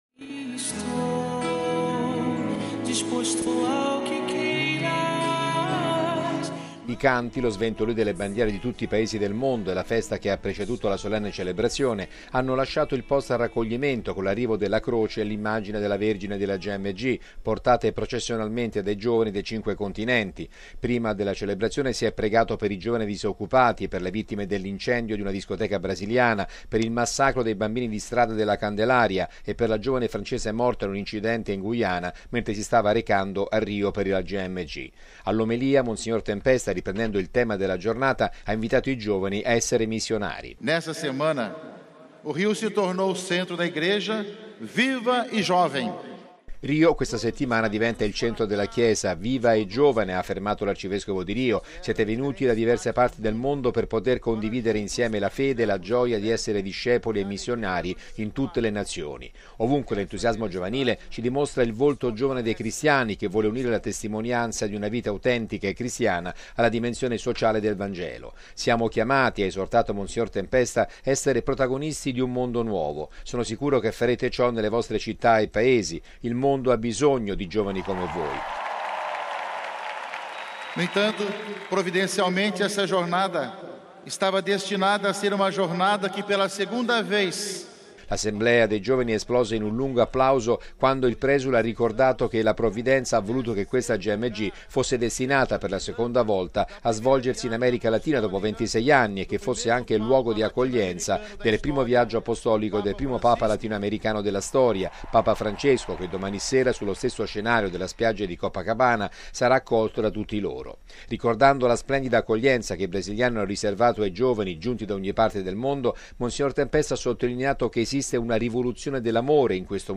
L’assemblea dei giovani è esplosa in un lungo applauso quando il presule ha ricordato che la Provvidenza ha voluto che questa Gmg fosse destinata, per la seconda volta, a svolgersi in America Latina dopo 26 anni e che fosse anche luogo di accoglienza del primo viaggio apostolico del primo Papa latinoamericano della storia, Papa Francesco che domani sera, sullo stesso scenario della spiaggia di Copacabana, sarà accolto da tutti loro.